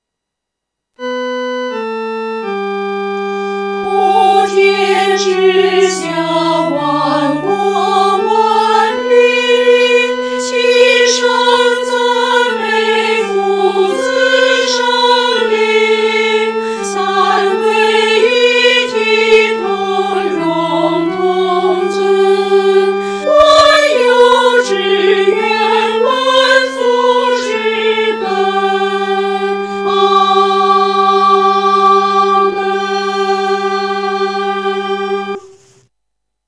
合唱
女高
诗班在指挥指导下练习这首诗歌时，各声部咬字字头鲜明有力，字腹响亮，字尾归韵要干净利落，气息支撑到位，混声音响要浑厚有力。